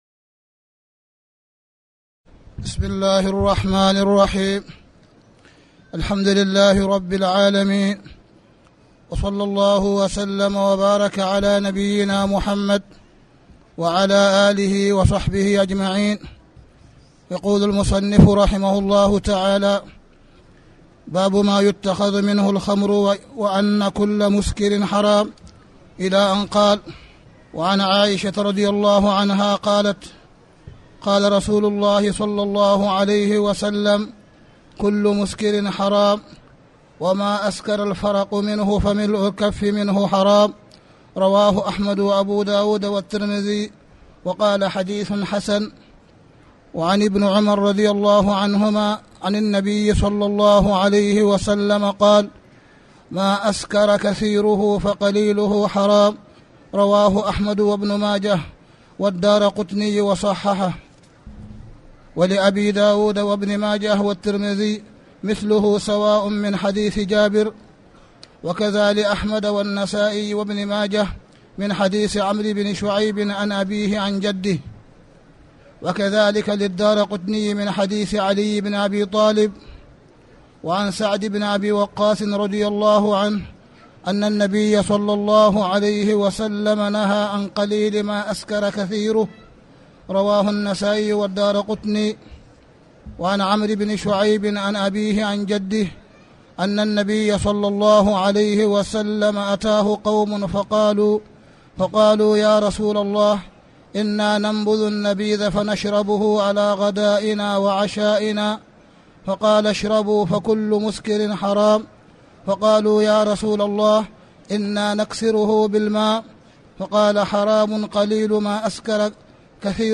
تاريخ النشر ٥ رمضان ١٤٣٩ هـ المكان: المسجد الحرام الشيخ: معالي الشيخ أ.د. صالح بن عبدالله بن حميد معالي الشيخ أ.د. صالح بن عبدالله بن حميد كتاب الأشربة